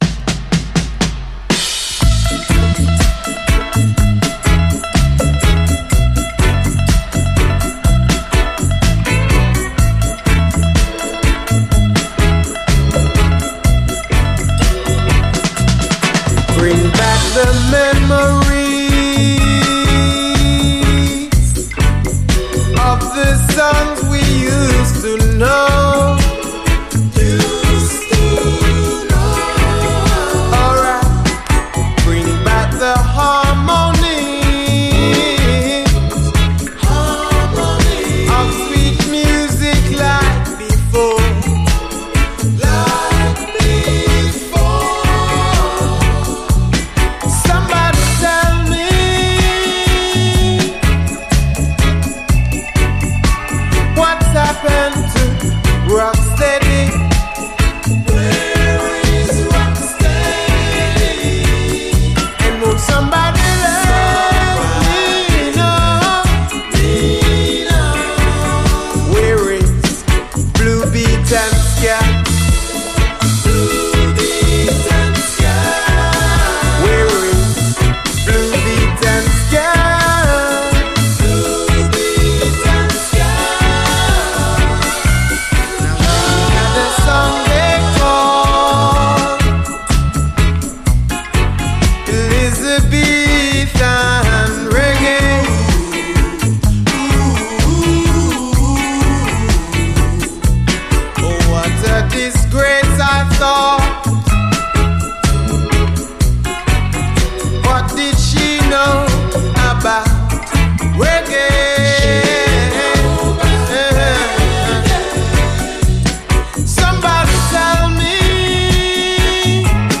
REGGAE
UKルーツの王道を行く、フワフワしたコーラスとキメ細やかなサウンド！